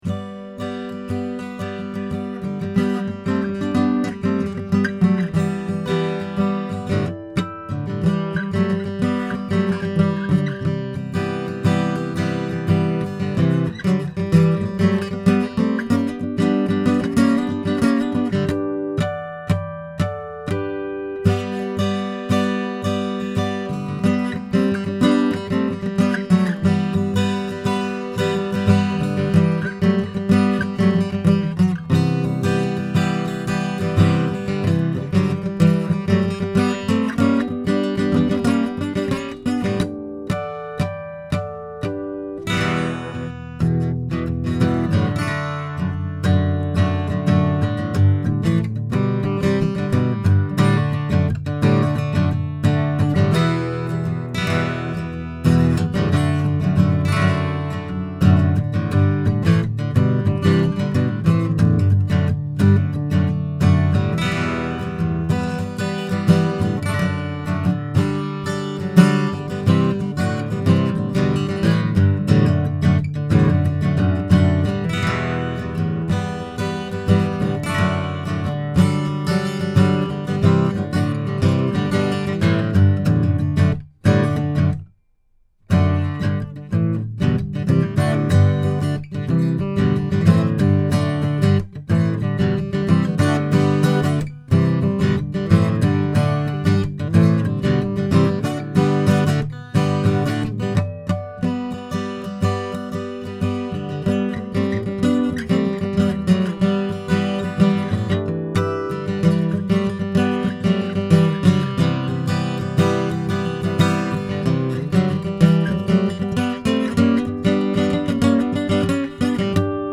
Here are some quick, 1-take MP3 sound files showing how each pattern sounds through a Presonus ADL 600 preamp into a Rosetta 200 A/D converter.
Santa Cruz OM/PW Guitar
FIG. 8 NULL SIDE